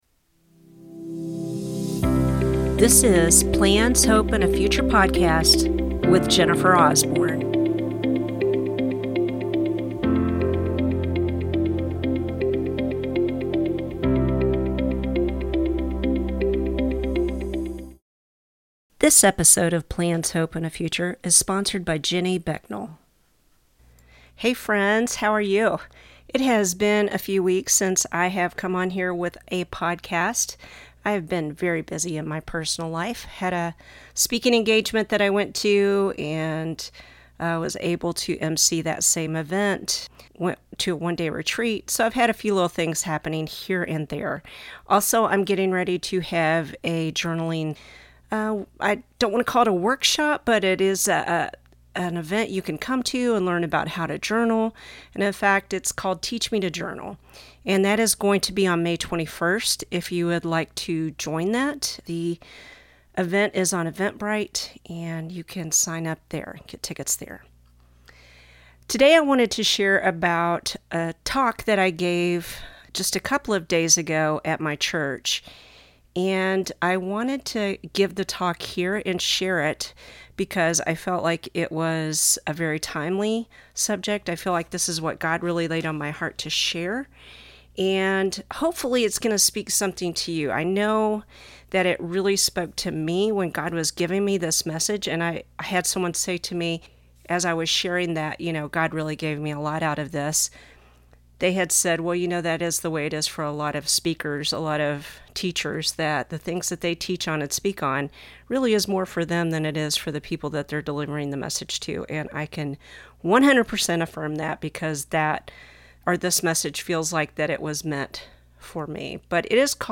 From my talk given at River of Life - April 12, 2025: